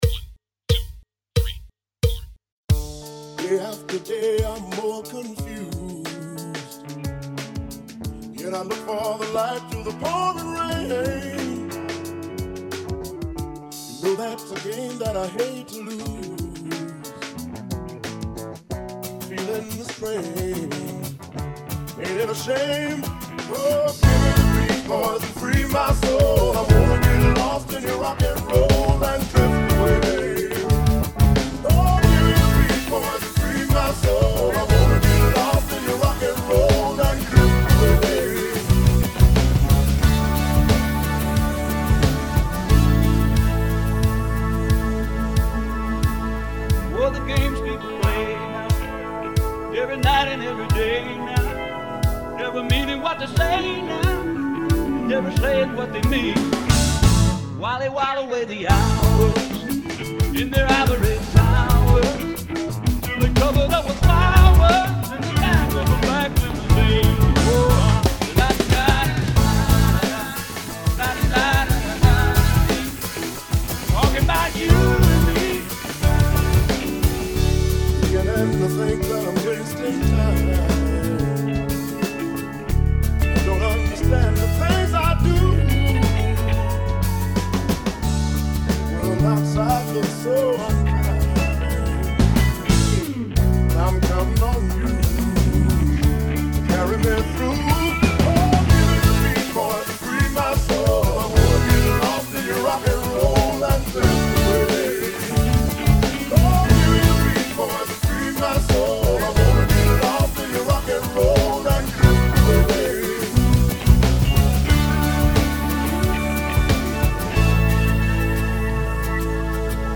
is a mash-up of two songs